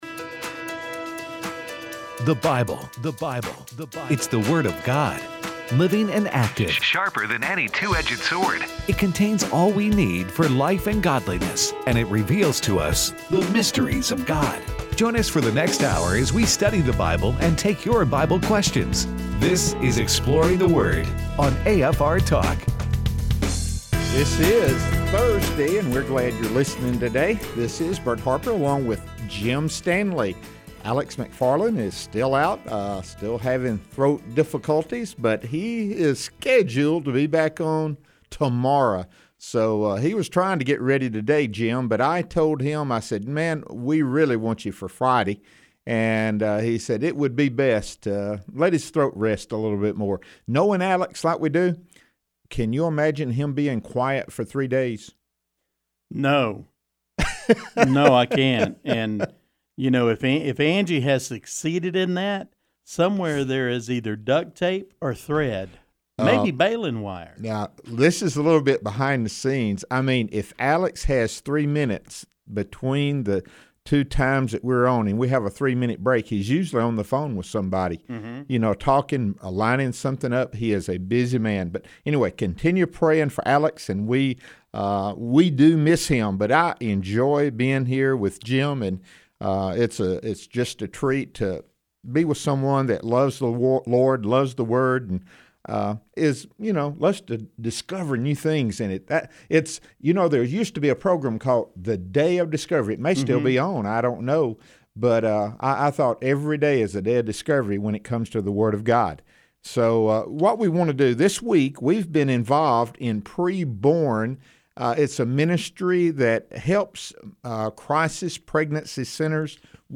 A ministry that we are partnering with to help raise money for crisis pregnancy clinics. They also take your phone calls in the last segment of the show.